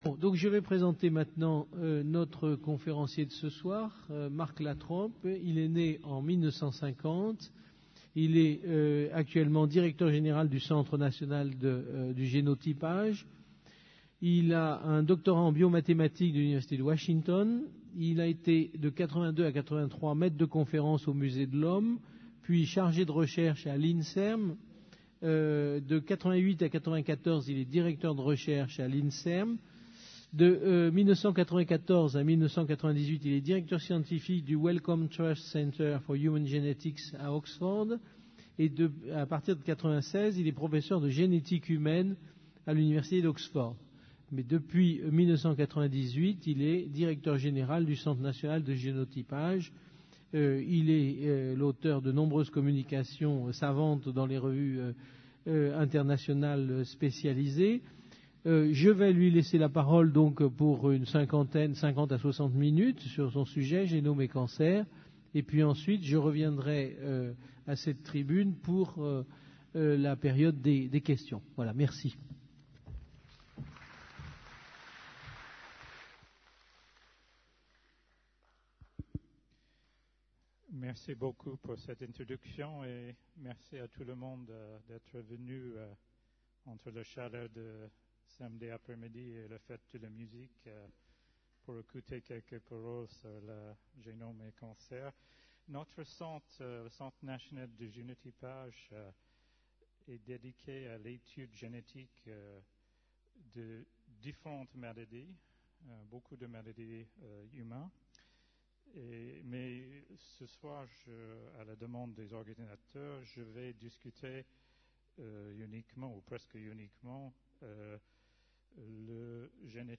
Une conférence du cycle : Qu'est ce que la vie ? Où en est la connaissance du génome ?